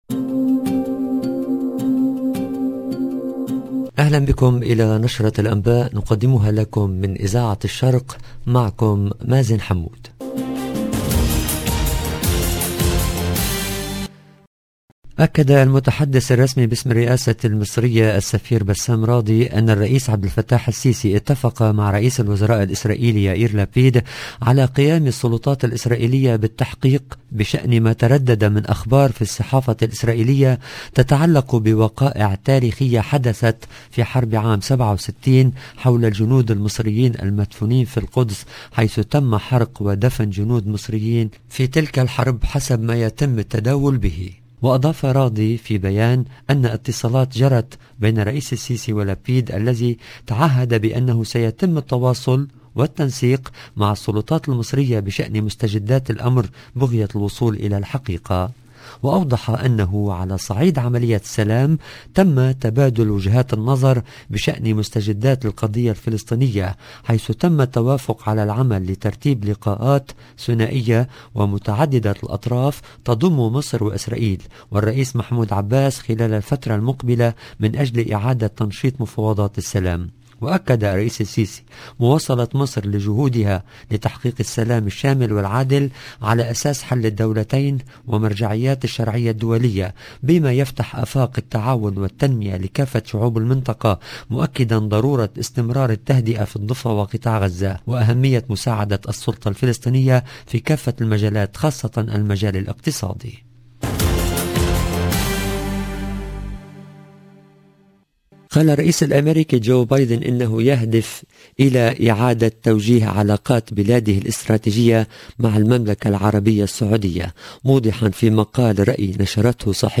LE JOURNAL EN LANGUE ARABE DU SOIR DU 11/07/22